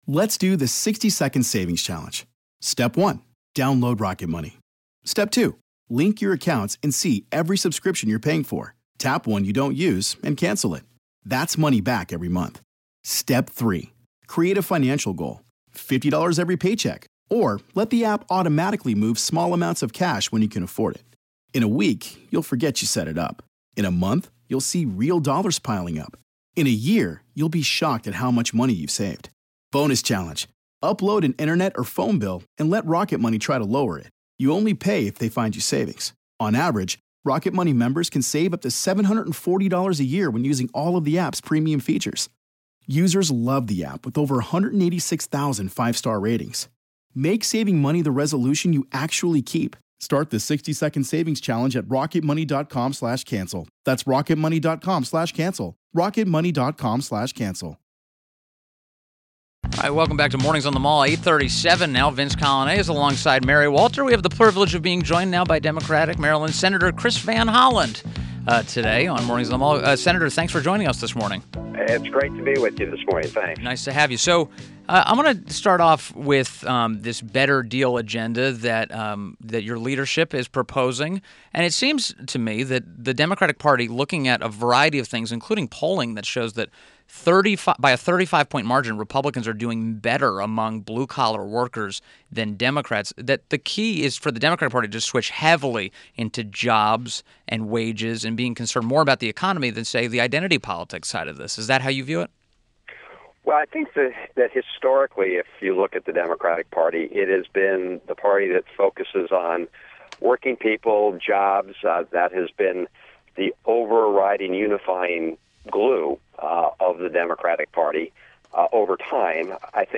INTERVIEW - SEN CHRIS VAN HOLLEN - D-MARYLAND